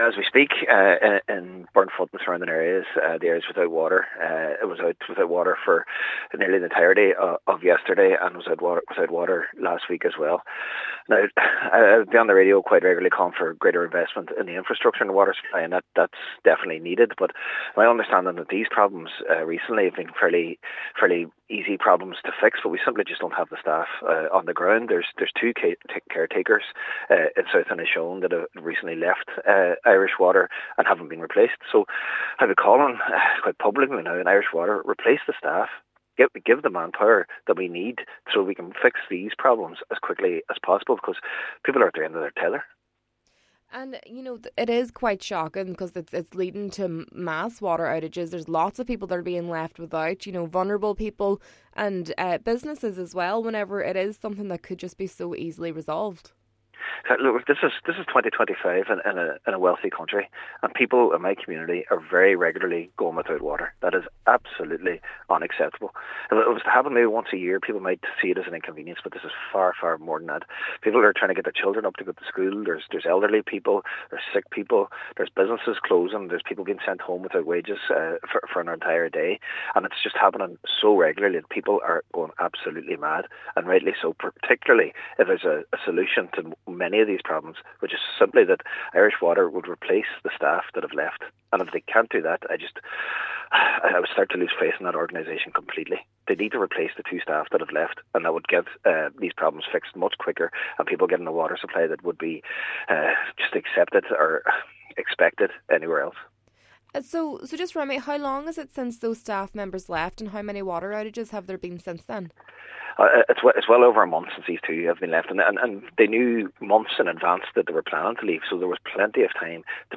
Cllr Murray says the staff left their roles a month ago, and Uisce Eireann needs to replace them as a matter of urgency: